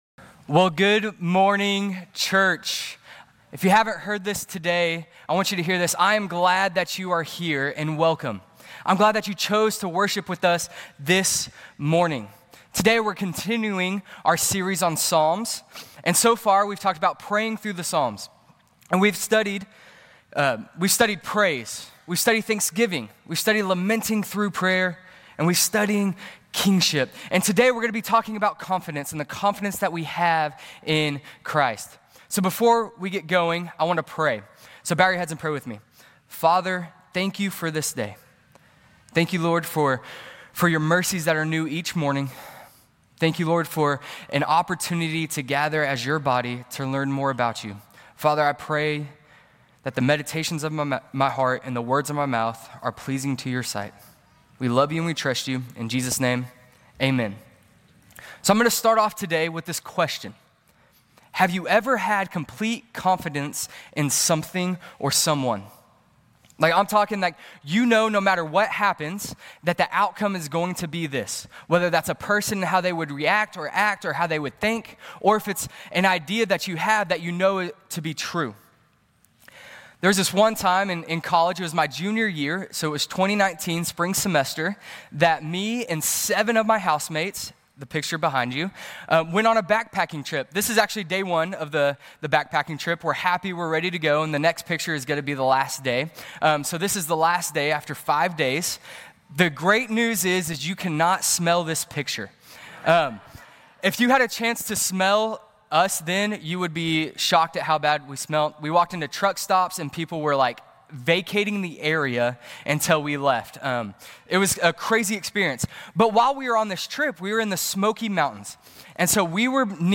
Grace Community Church Lindale Campus Sermons Psalm 23 - Confidence Jul 21 2024 | 00:22:40 1x 00:00 / 00:22:40 Subscribe Share RSS Feed Share Link Embed